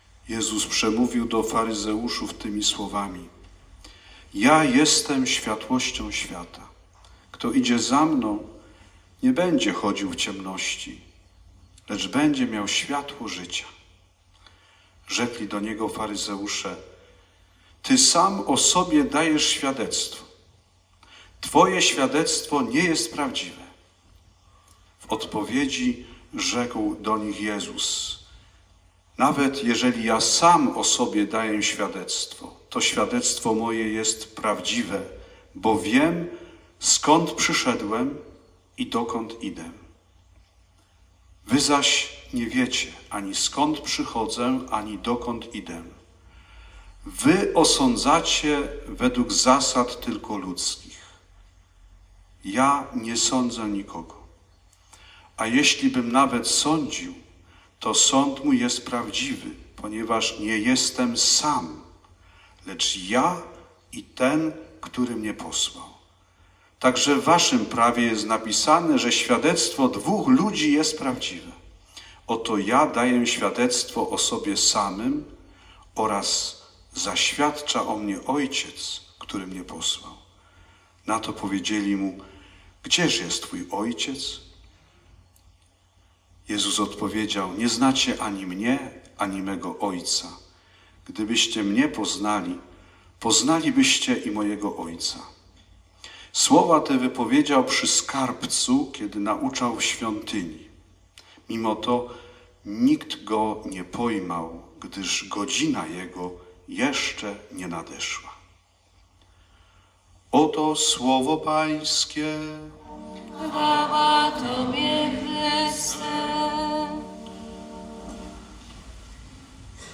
konferencje wygłoszone w trakcje rekolekcji parafialnych